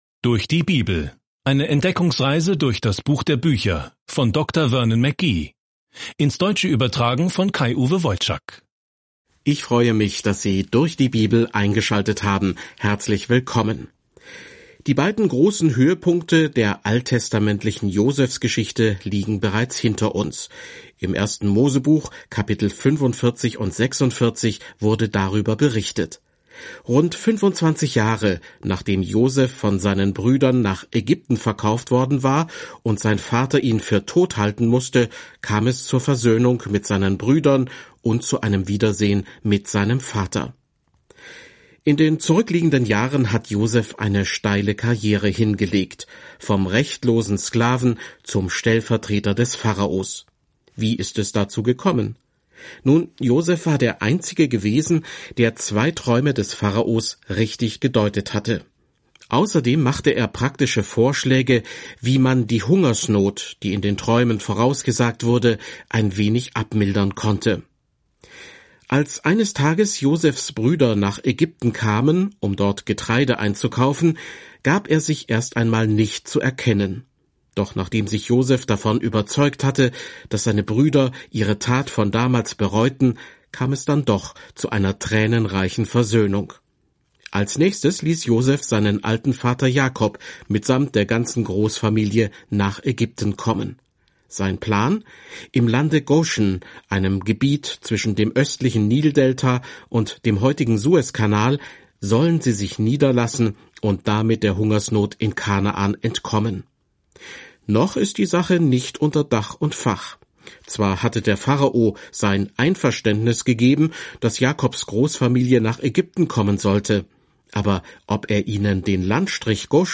Mose 47 Tag 50 Diesen Leseplan beginnen Tag 52 Über diesen Leseplan Hier beginnt alles – das Universum, die Sonne und der Mond, Menschen, Beziehungen, Sünde – alles. Reisen Sie täglich durch die Genesis, während Sie sich die Audiostudie anhören und ausgewählte Verse aus Gottes Wort im Buch Genesis lesen.